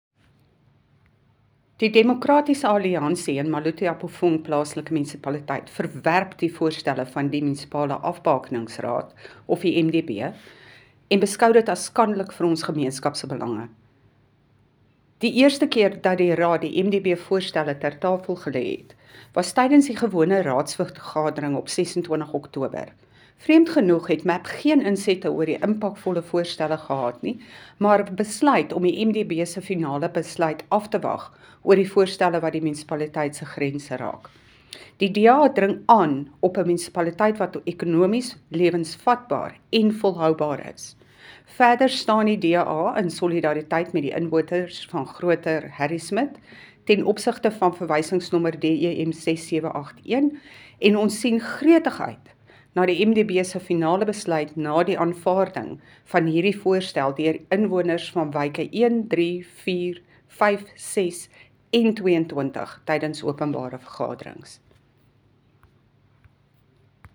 Afrikaans by Cllr Bea Campbell-Cloete.